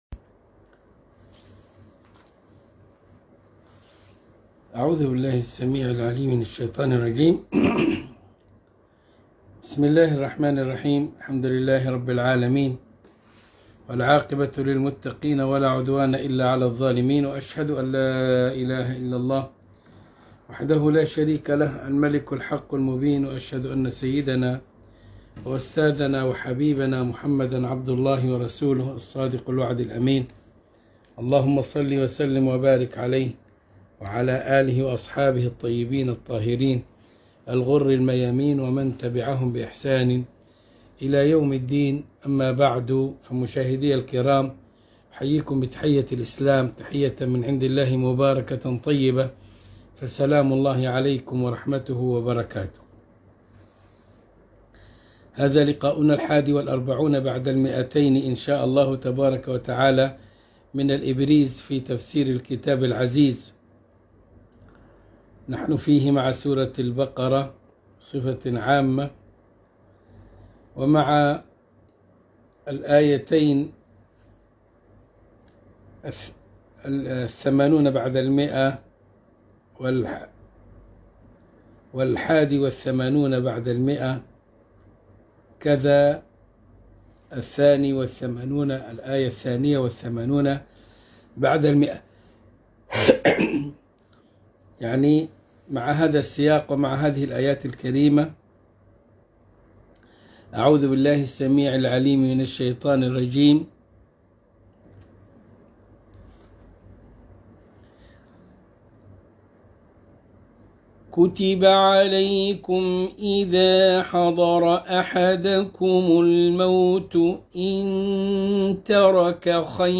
الدرس ٢٤١ من الإبريز في تفسير الكتاب العزيز سورة البقرة الآية١٨٠ وما بعدها